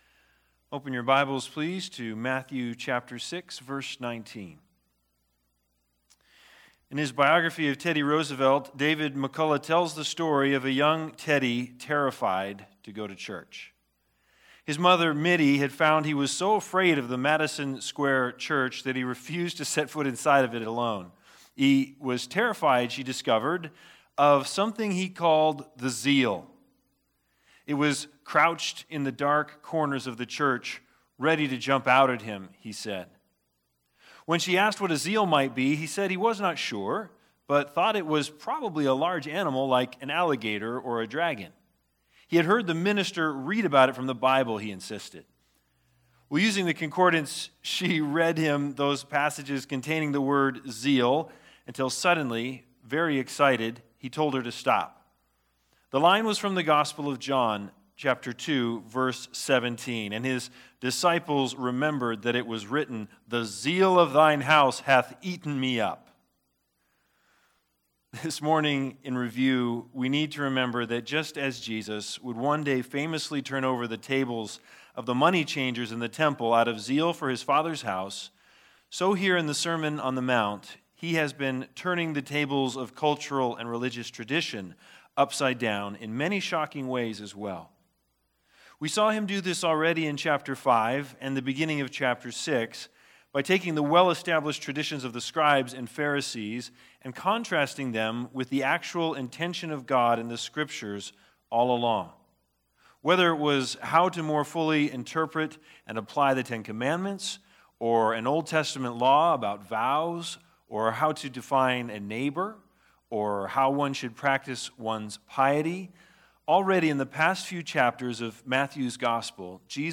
Sunday Sermons The Big Idea